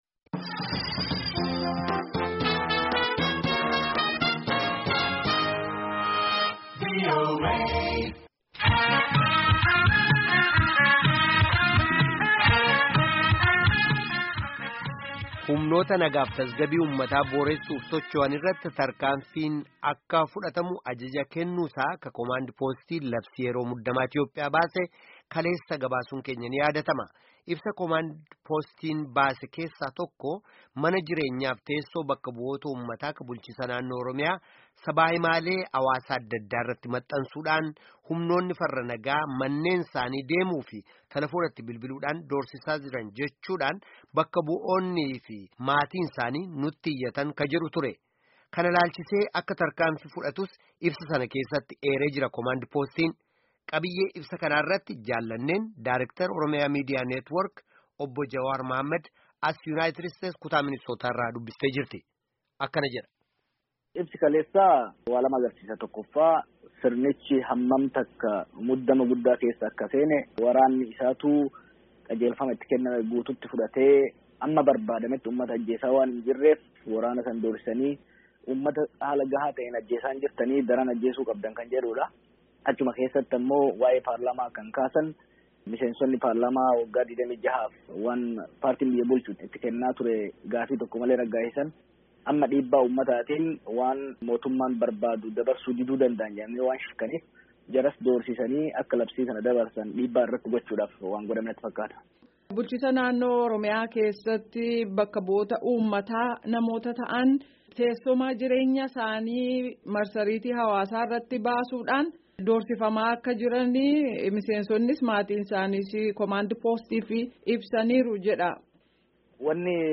Daayrekteera Oromia Media Network (OMN) Obbo Jawaar Mohammad waliin gaaffii fi debii gaggeefame Caqasaa.